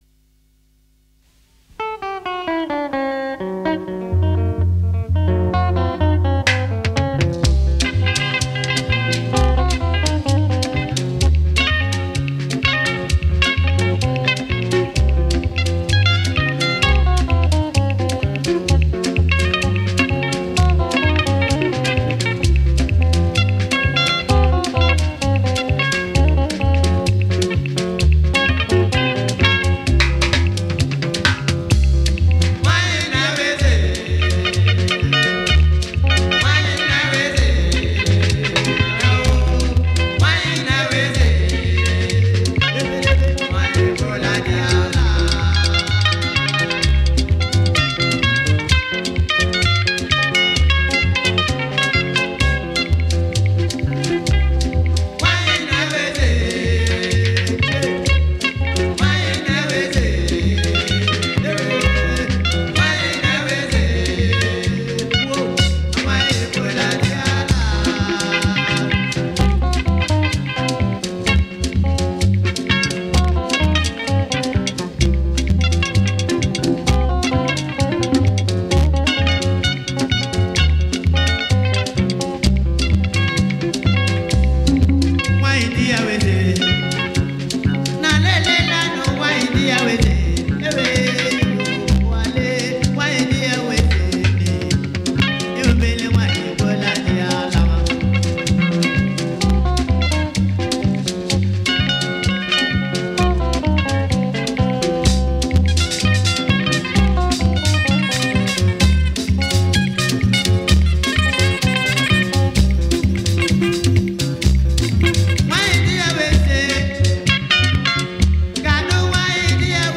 was a Nigerian orchestra high life band from Eastern Nigeria